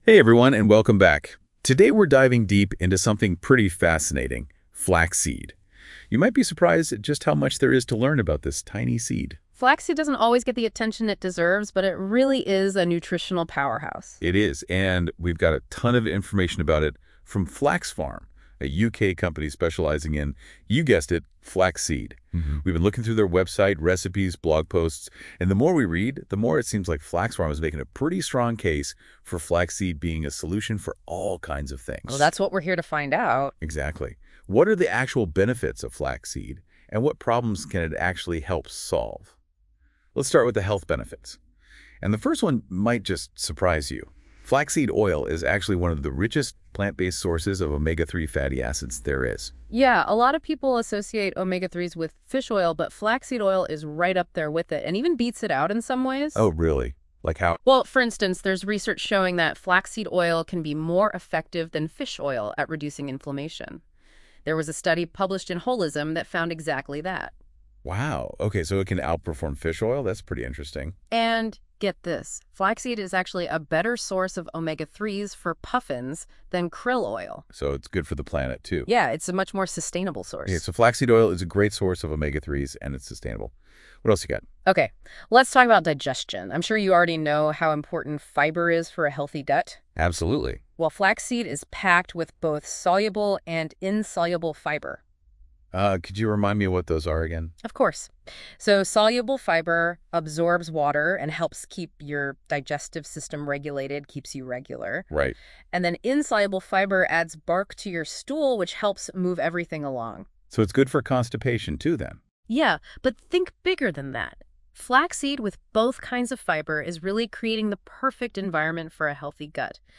This audio is an introduction to us and our products, it was generated as an introduction to ourselves by the Gods of Artificial Intelligence.